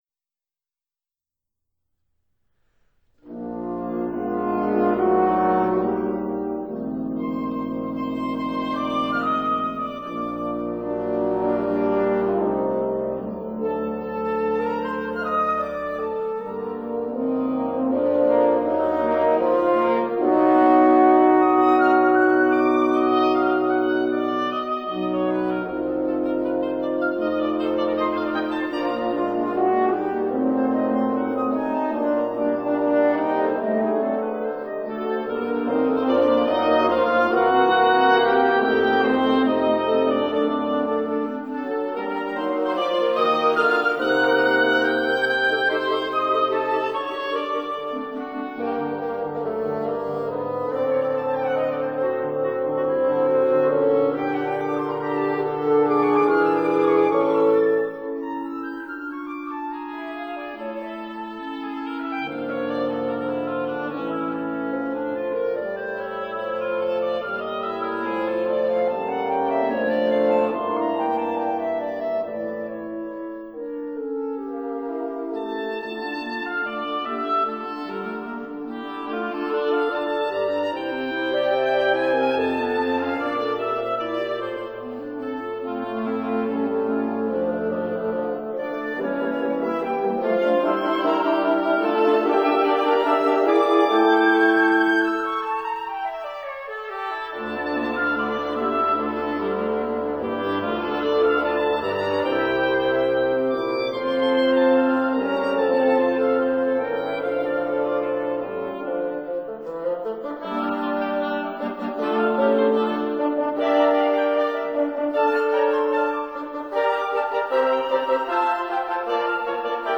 flute
oboe
clarinet
horn
bassoon
piano